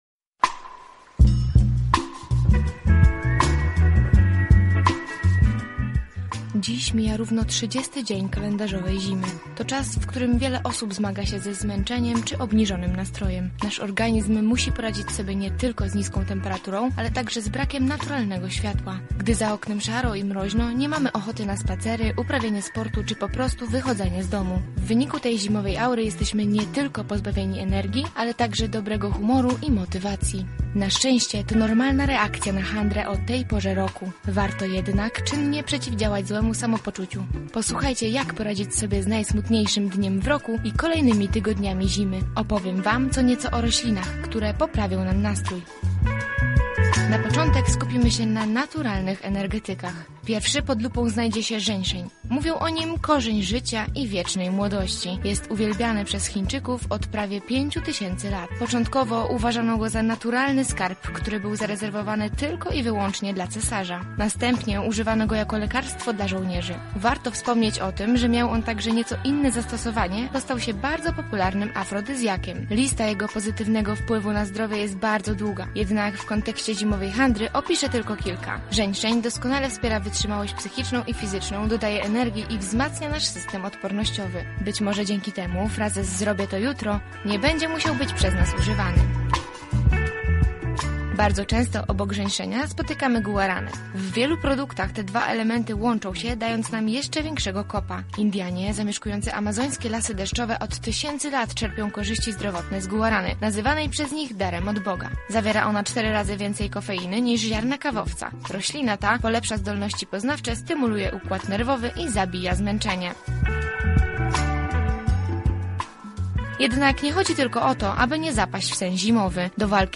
Nasza reporterka zapytała przechodniów, jak się czuli w Blue Monday:
Sonda